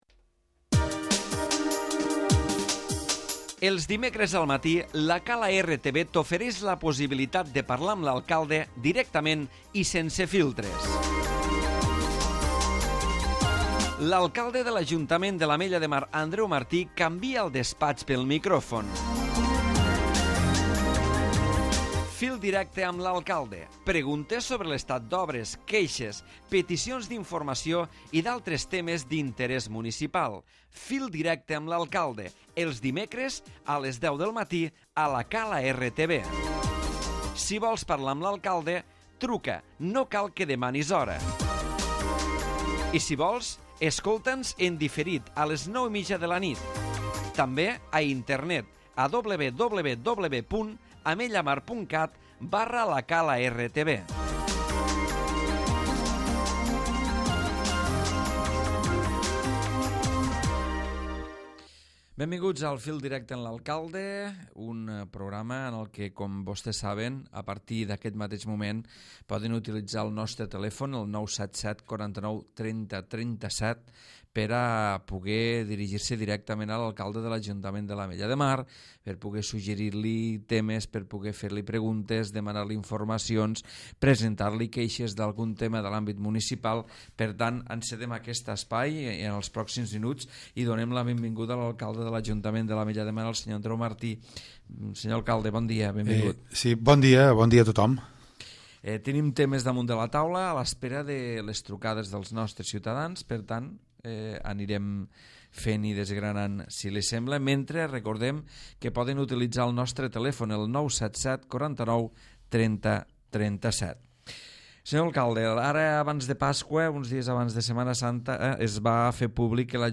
Andreu Martí, alcalde de l'Ajuntament de l'Ametlla de Mar, analitza als micròfons de la ràdio municipal el dia a dia de l'Ajuntament i atén les trucades dels ciutadans.